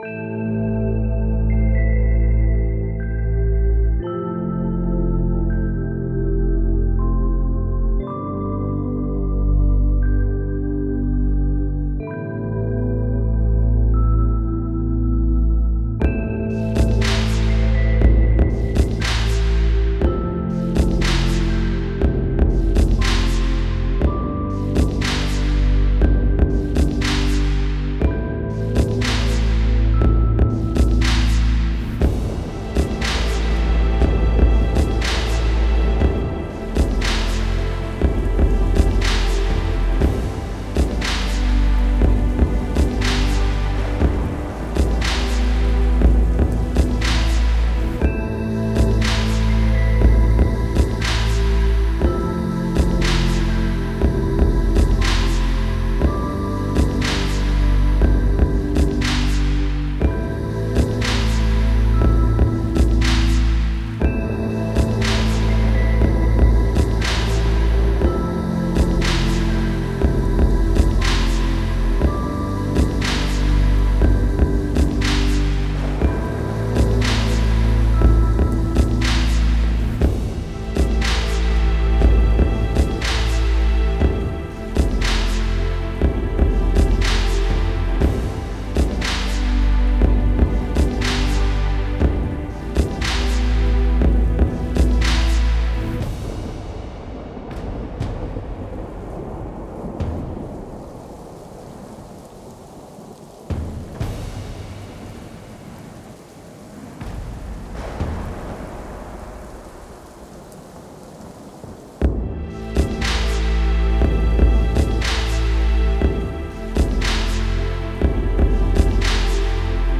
Стиль: Chillout/Lounge / Ambient/Downtempo